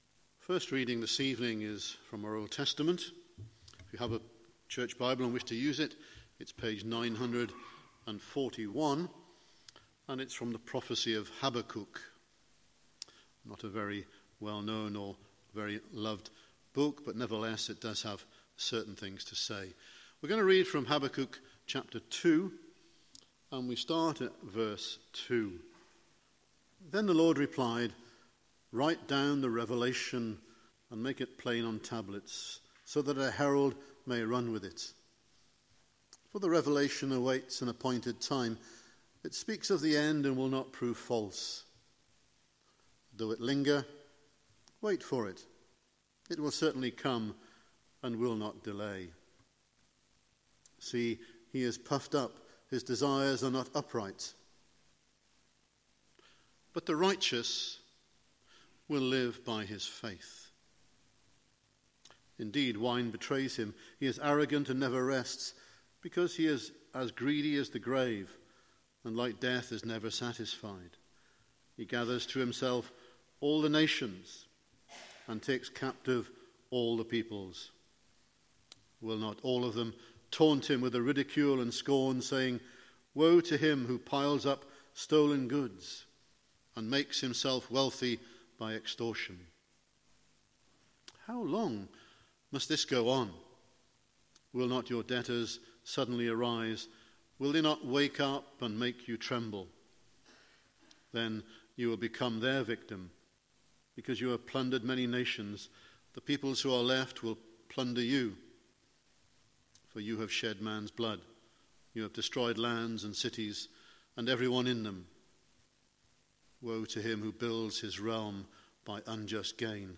Guest Speaker , Evening Service